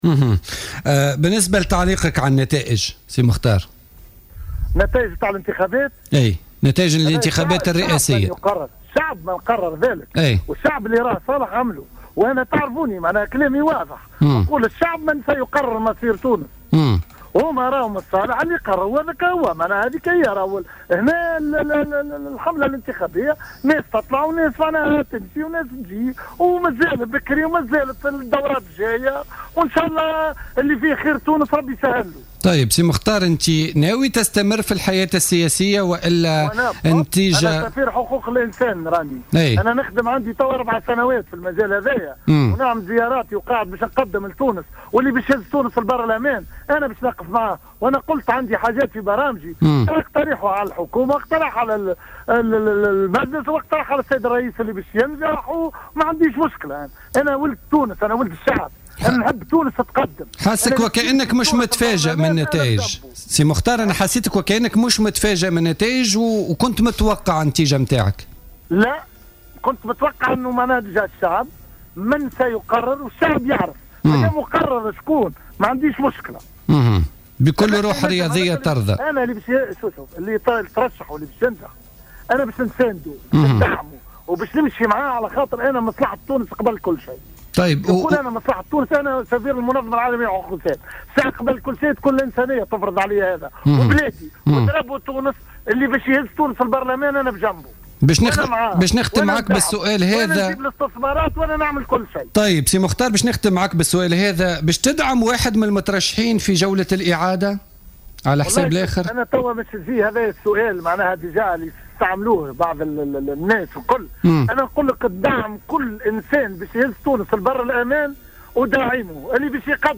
علّق مختار الماجري المرشح المستقل للانتخابات الرئاسية الفارطة في مداخلة له في برنامج "بوليتيكا" على نتائج الانتخابات، مشيرا إلى أن الشعب هو صاحب القرار وأنه يحترم إرادته.